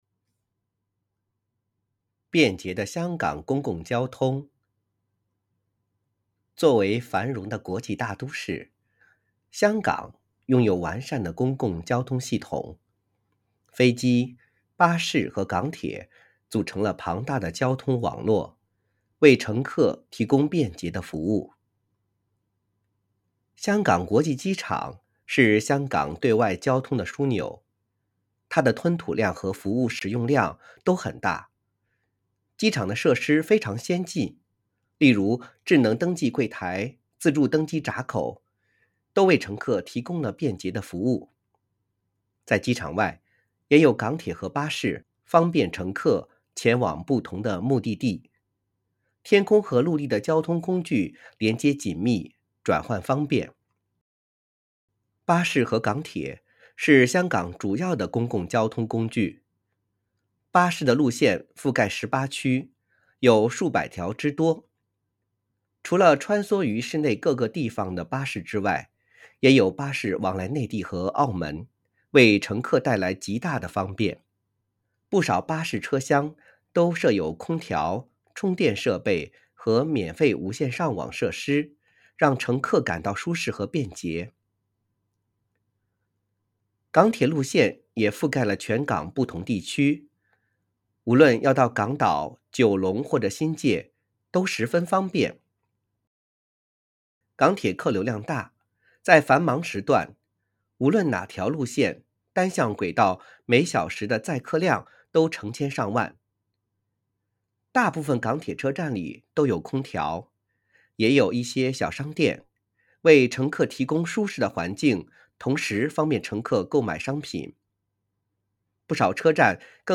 編號篇名篇章及學與教建議朗讀示範篇章附拼音